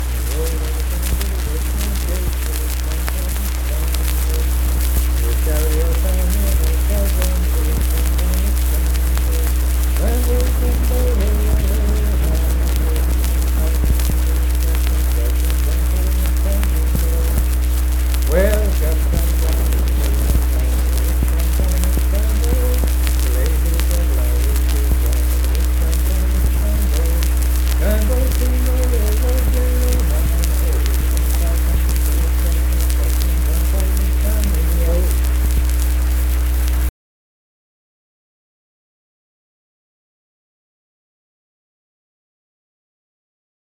Unaccompanied vocal music
Verse-refrain 2(4w/R) &R(4). Performed in Kanawha Head, Upshur County, WV.
Voice (sung)